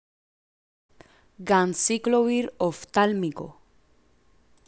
Pronunciar: